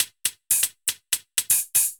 UHH_ElectroHatD_120-04.wav